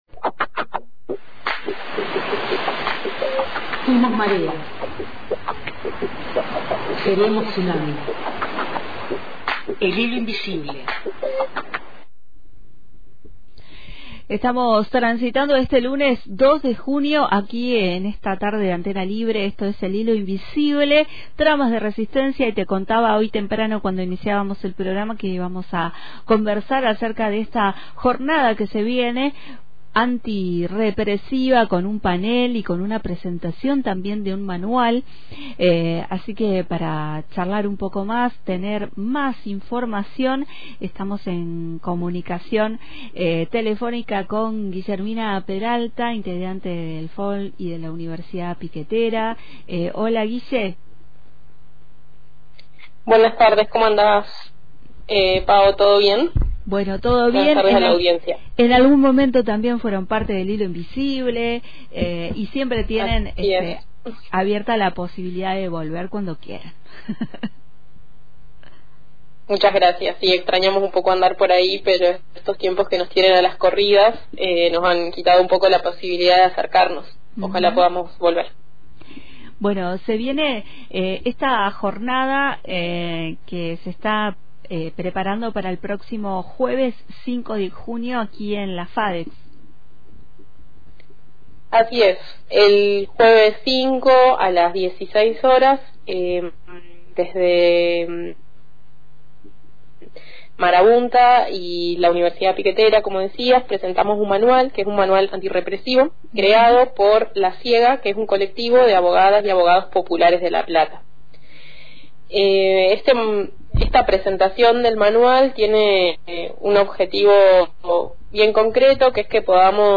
El manual, fruto de talleres con diversos colectivos, aborda desde cómo actuar frente a detenciones hasta estrategias de resistencia ante allanamientos, represión virtual y criminalización de redes. Consultada por Antena Libre